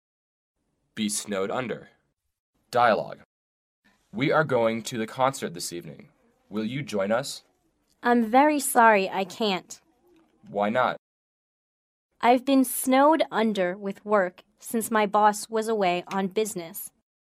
英语情景对话：